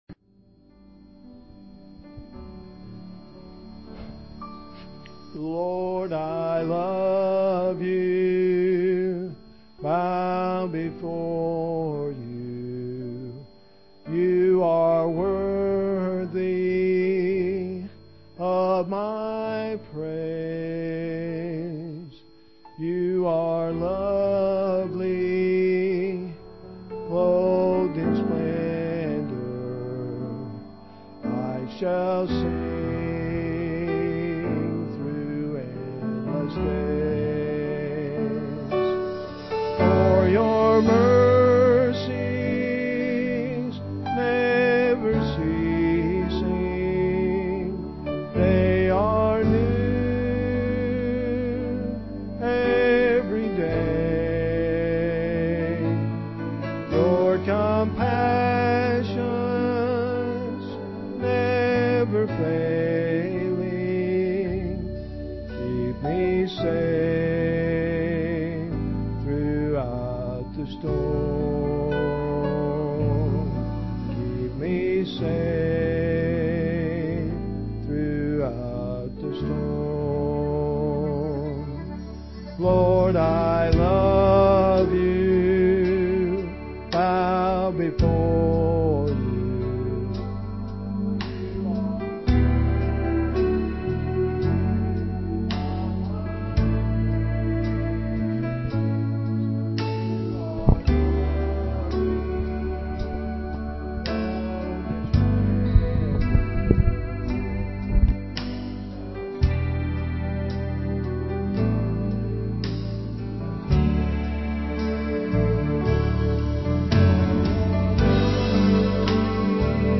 piano and organ
vocal solo and guitar
guitar accompaniment